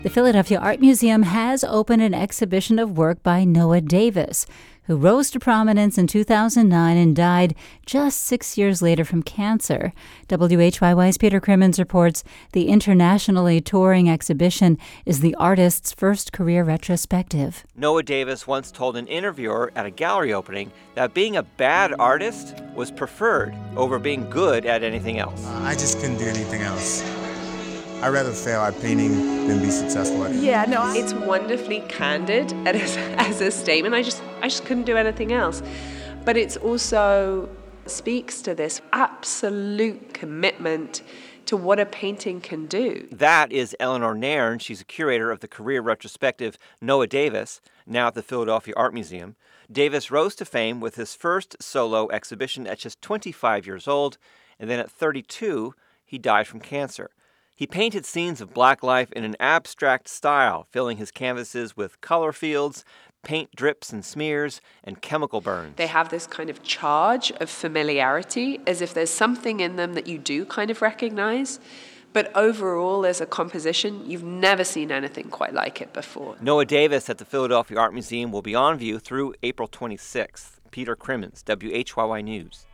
On Sunday, inside LaSalle University’s Hayman Center, to the sounds of basketballs bouncing off the wooden floor, they shot hoops to help Philadelphia’s homeless get the rebound necessary to find shelter of their own.